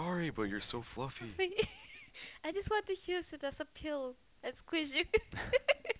Supporting Information for Analyses of L2 English
3. "pillow" (alignment): Dimension 10 low